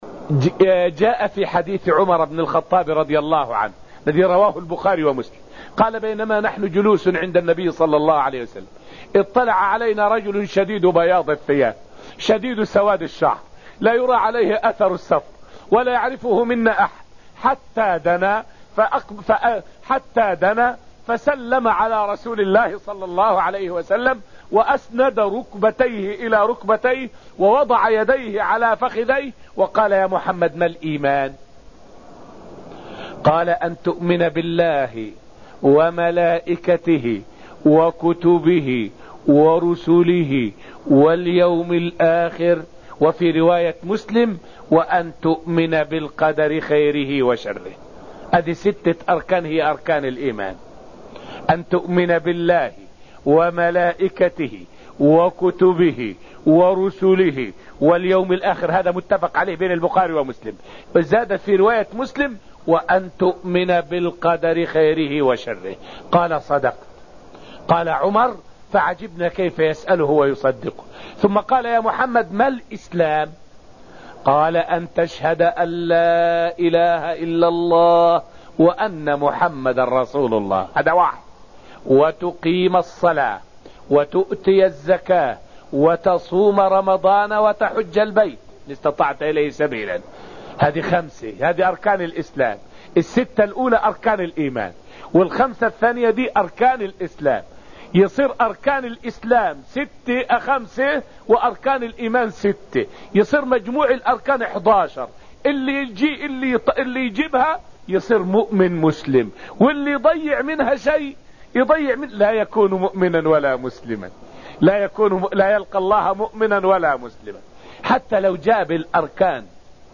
فائدة من الدرس الرابع من دروس تفسير سورة الذاريات والتي ألقيت في المسجد النبوي الشريف حول تحقيق الإسلام والإيمان شرطٌ للنجاة.